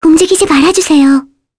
Mirianne-Vox_Skill5_kr.wav